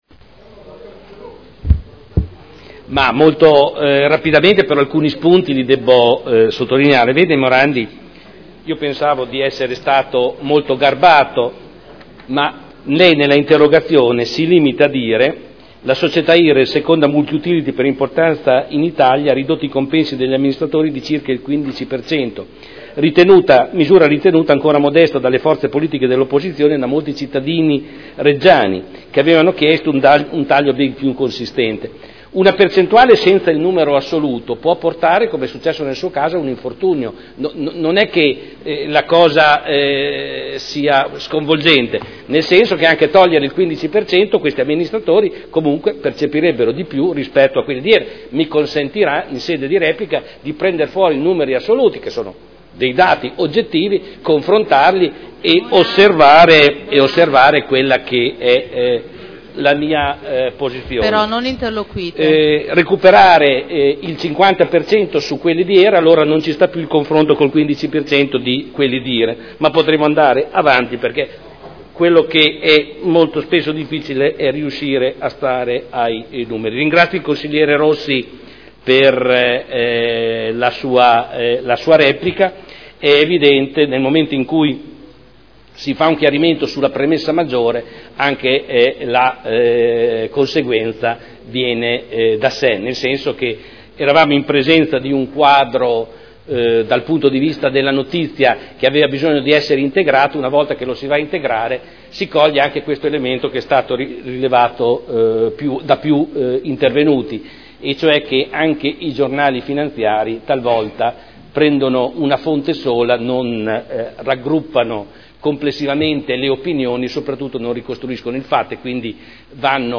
Giorgio Pighi — Sito Audio Consiglio Comunale
Seduta del 28/02/2011.